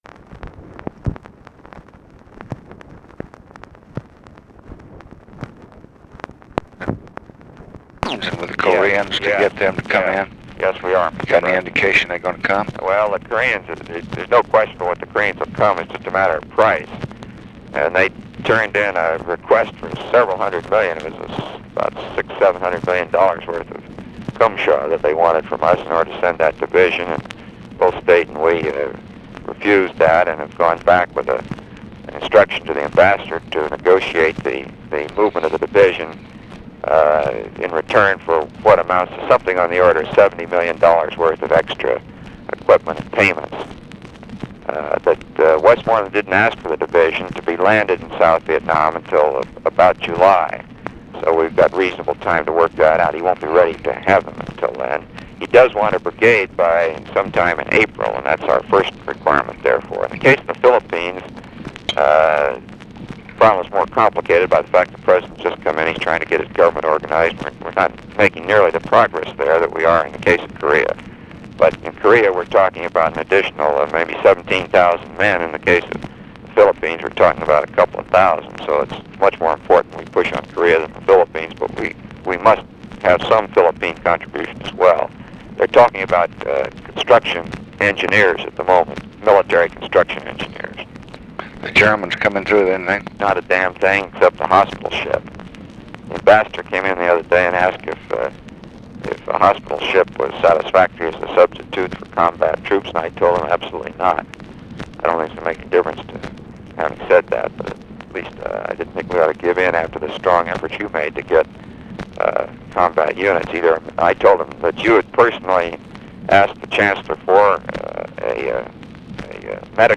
Conversation with ROBERT MCNAMARA, January 17, 1966
Secret White House Tapes